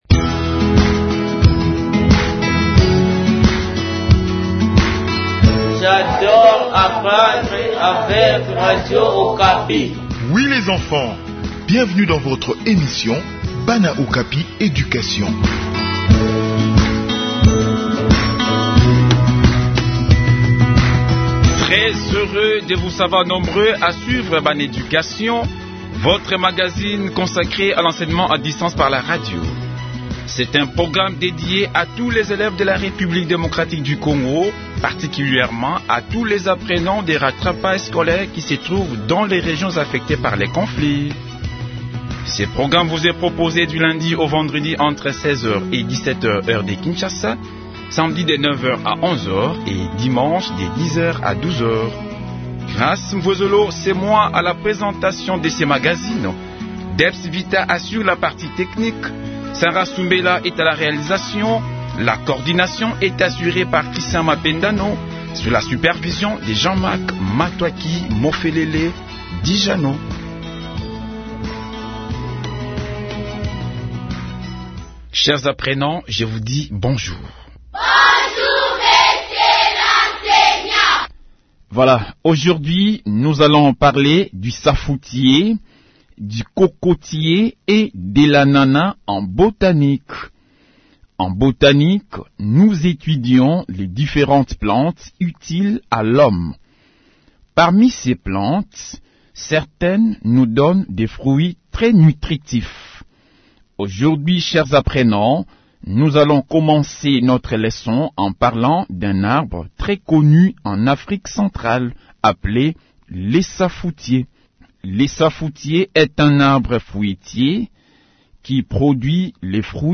Enseignement à distance : leçon de Botanique sur le safoutier, le cocotier et l'ananas.